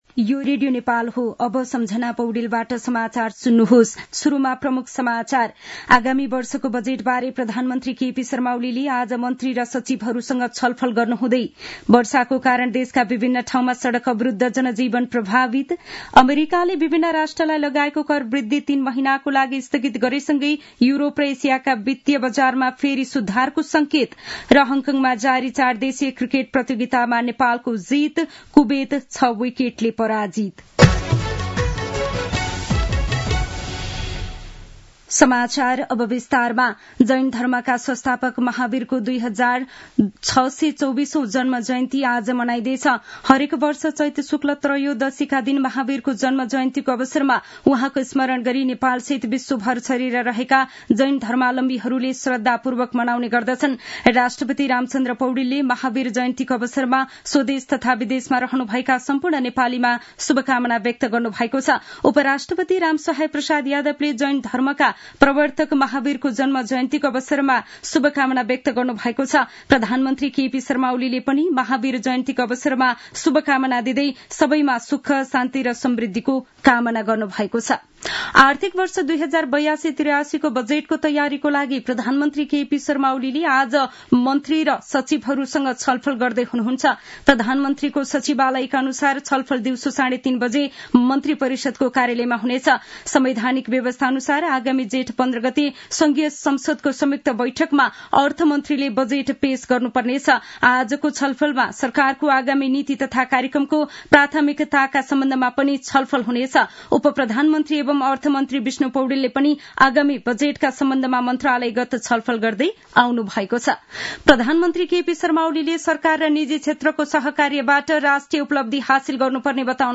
दिउँसो ३ बजेको नेपाली समाचार : २८ चैत , २०८१
3-pm-news-1-2.mp3